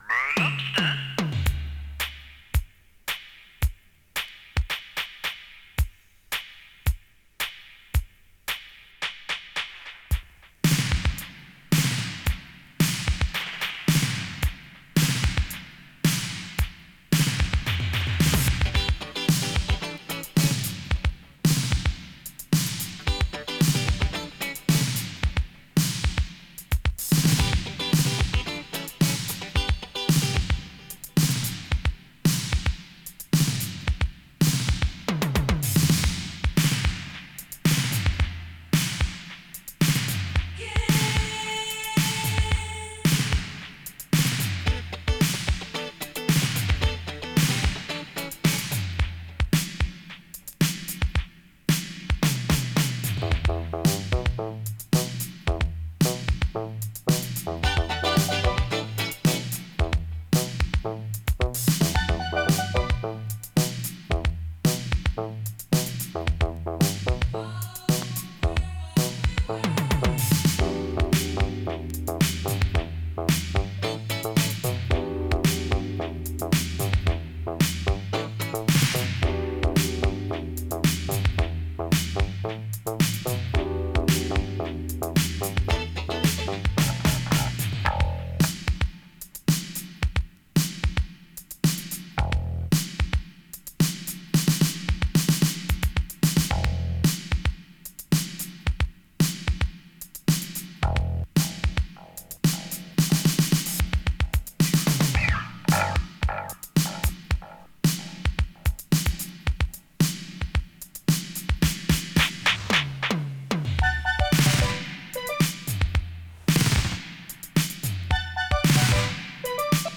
Electro Funk!
UK産エレクトロ・グループ。
ラップ入りのエレクトロ・トラックですが、トロピカルなＢサイドのインスト・ヴァージョンが最高！